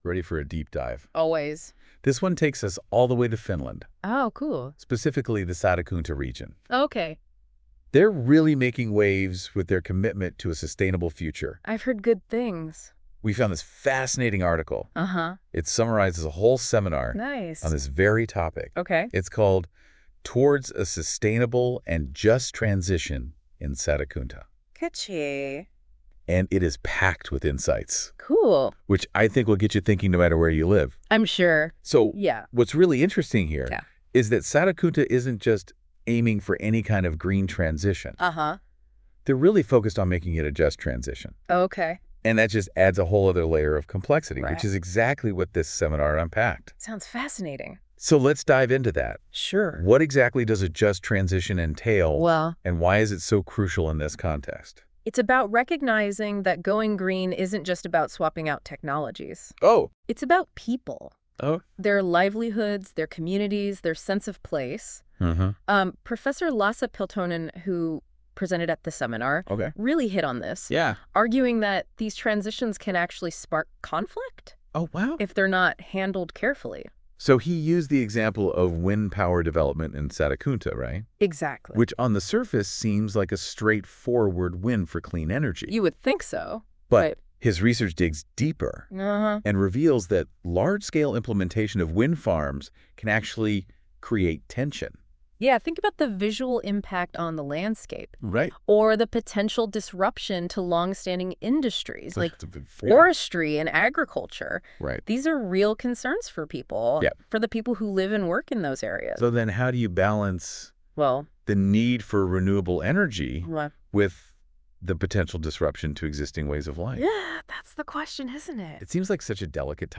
Sustainable transition in Satakunta podcast by Google Notebook LM